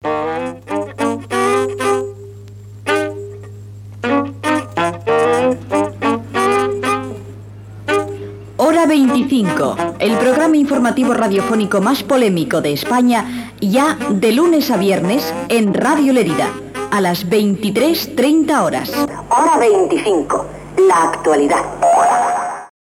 Promoció del programa que es comença a emetre per Radio Lérida aquell dia